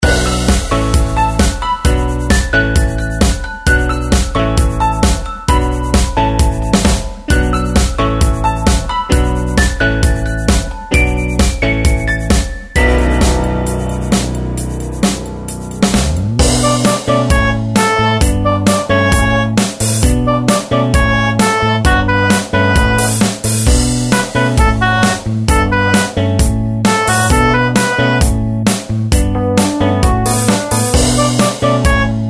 Tempo: 132 BPM.
MP3 with melody DEMO 30s (0.5 MB)zdarma